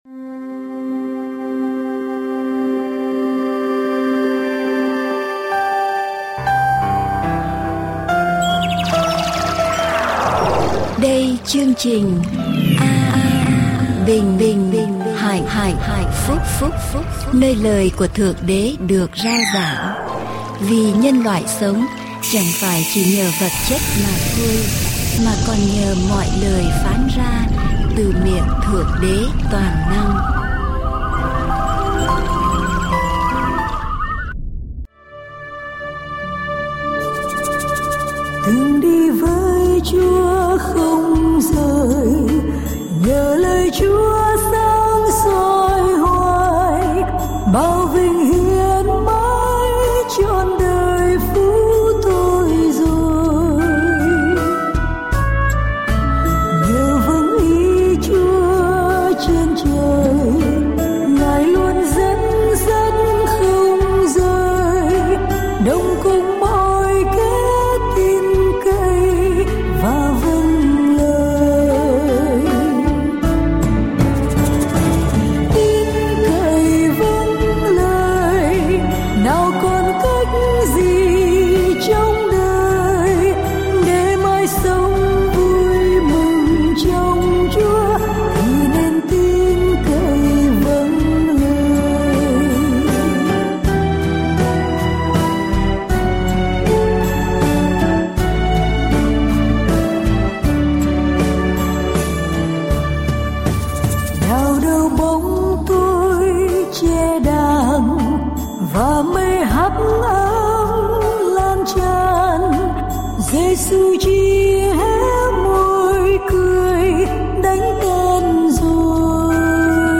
Adventist Vietnamese Sermon